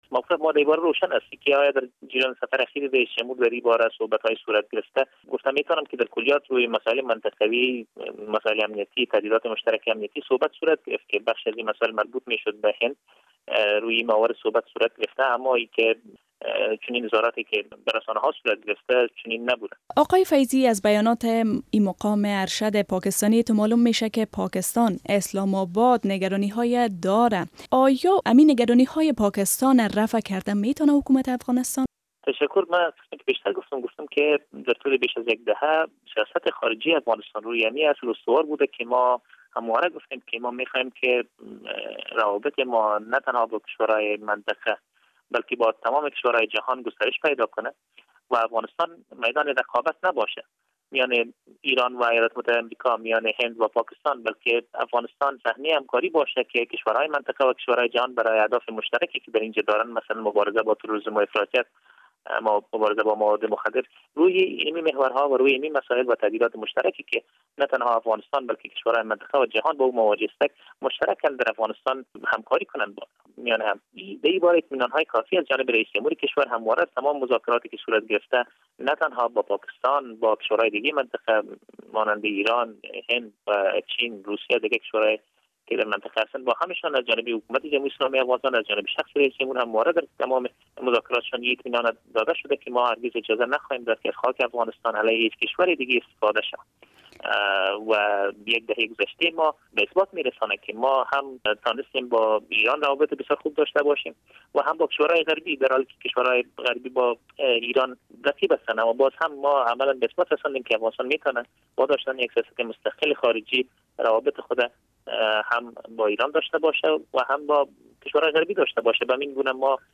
مصاحبه با ایمل فیضی در مورد اظهارات سرتاج عزیز مشاور صدراعظم پاکستان